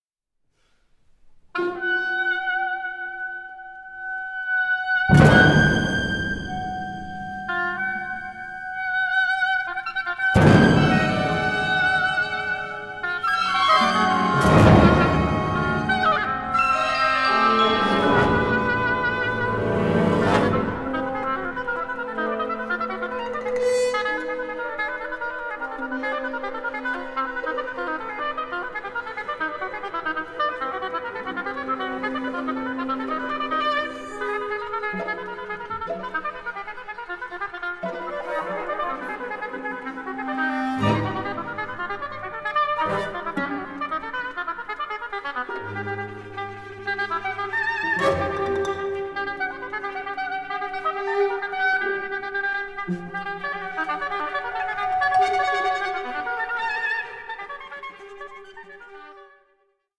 Concerto for Oboe and Orchestra